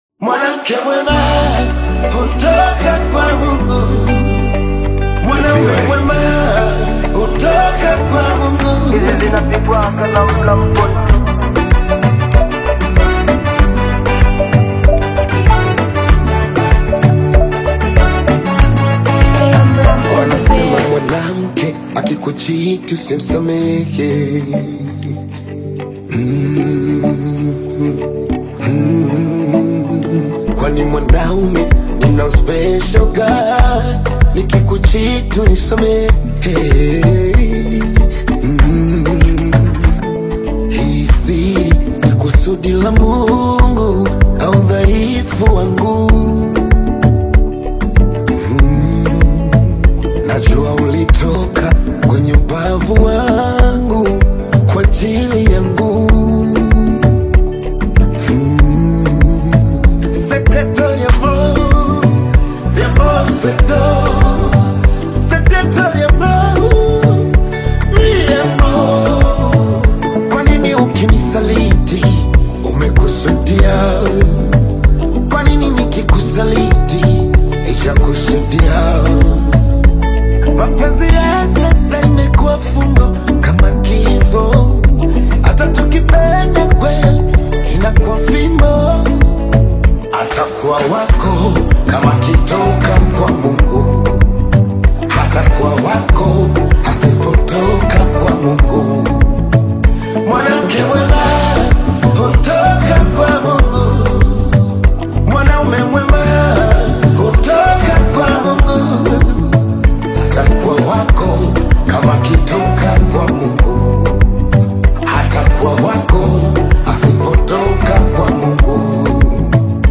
AfrobeatAudioBongo flava
gospel-inspired Bongo-Flava single
heartfelt vocals